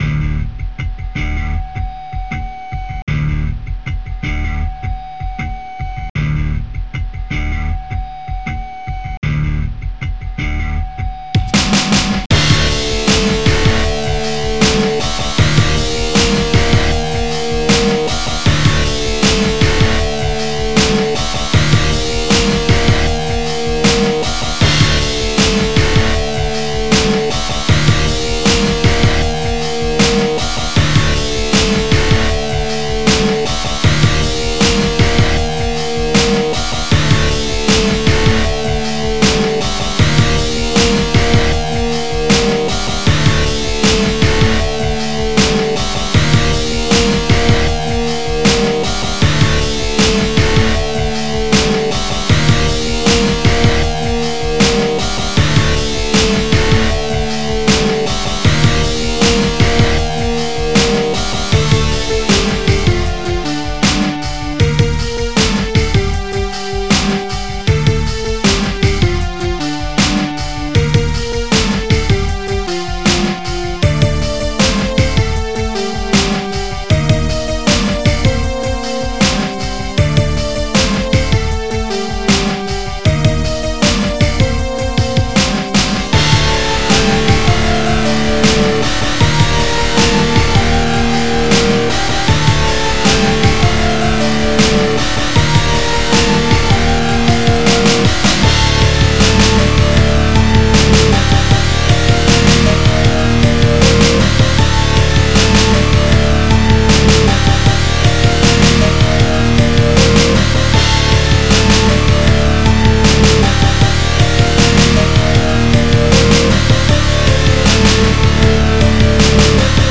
SnareDrum
BassDrum
HighHat
Elec Guitar
SynthLead#1
CymbalCrash
Elec Bass